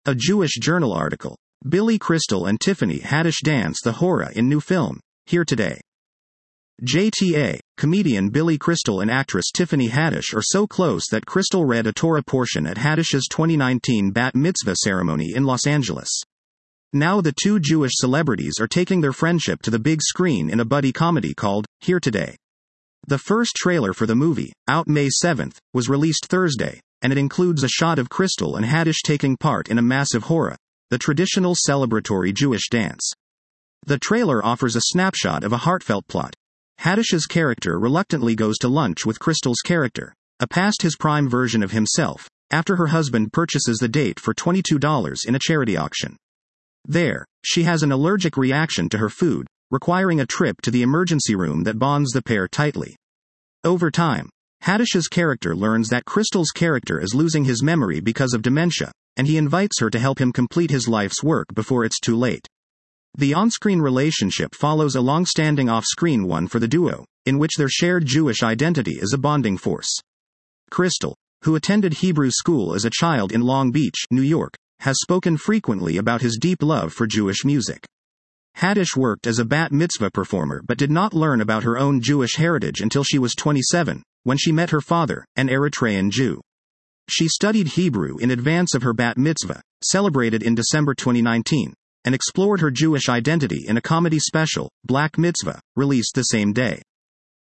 The first trailer for the movie, out May 7, was released Thursday, and it includes a shot of Crystal and Haddish taking part in a massive hora, the traditional celebratory Jewish dance.